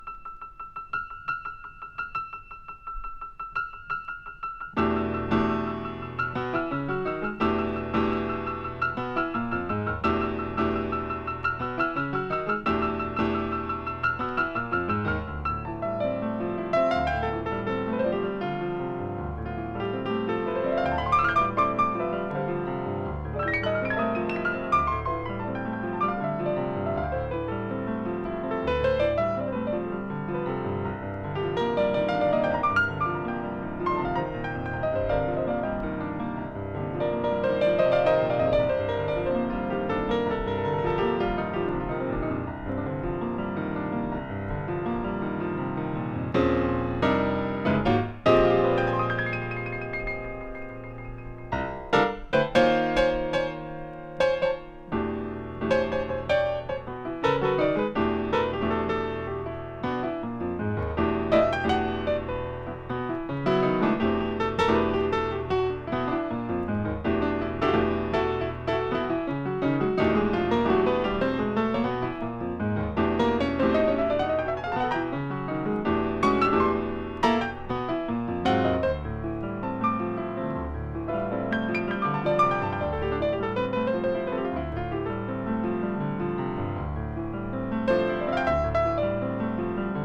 共通のテーマ曲、カヴァー曲、オリジナル曲を3曲ずつ収録したPCM録音盤。